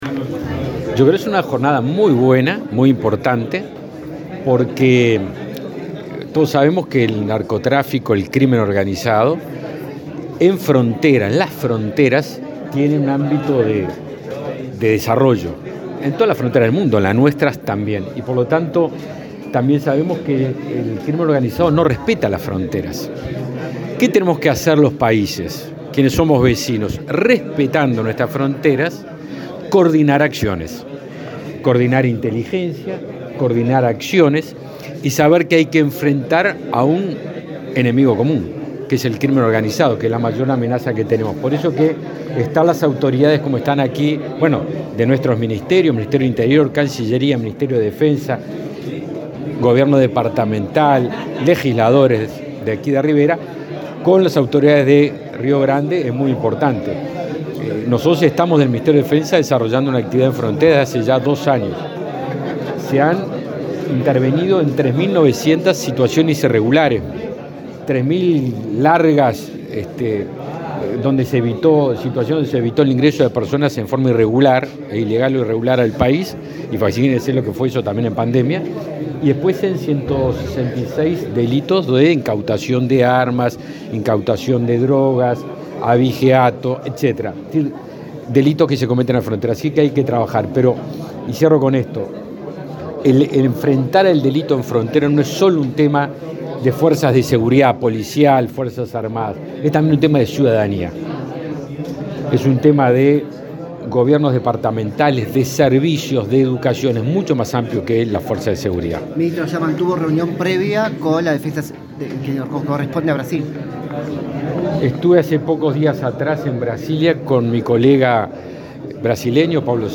Declaraciones a la prensa del ministro de Defensa, Javier García
Declaraciones a la prensa del ministro de Defensa, Javier García 30/08/2022 Compartir Facebook X Copiar enlace WhatsApp LinkedIn El ministro del Interior, Luis Alberto Heber; el canciller, Francisco Bustillo, y el ministro de Defensa Nacional, Javier García, participaron en Rivera en un encuentro binacional entre Uruguay y Brasil sobre seguridad pública. Luego, García dialogó con la prensa.